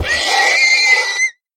sounds / mob / horse / death.mp3
death.mp3